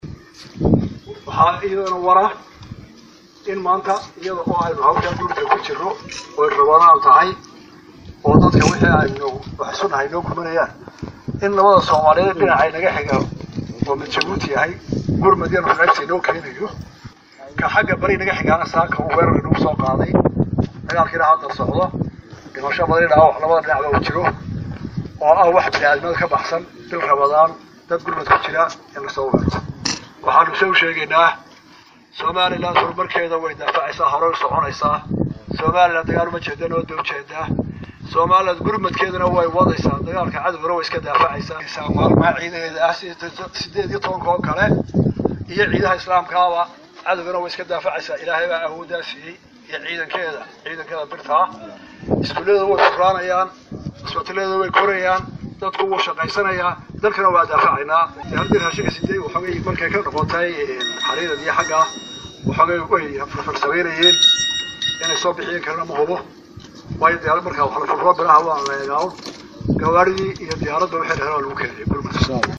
Madaxweynaha Somaliland Muuse Biixi Cabdi ayaa ka hadlay dagaal saakay Somaliland iyo Puntland ku dhexmaray deegaanka Tukaraq ee gobolka Sool.